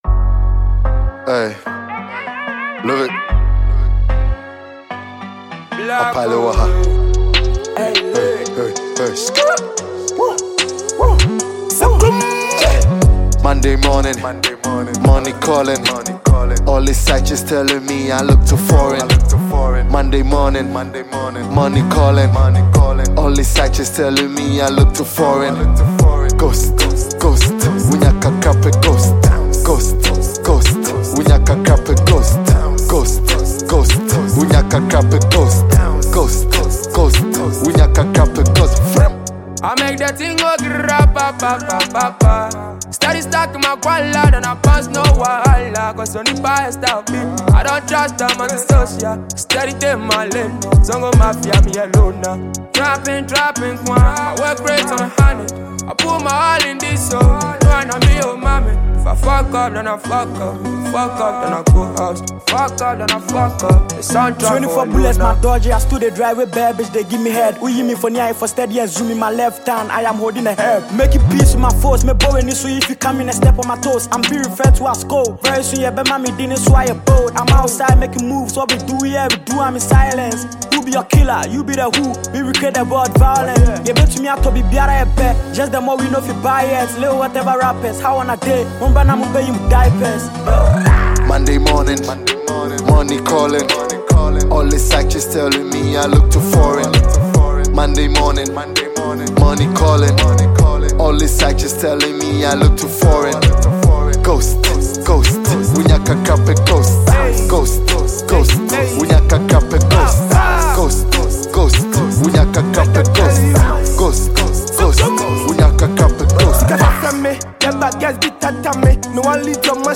Ghana Music Music
Ghanaian rapper and singer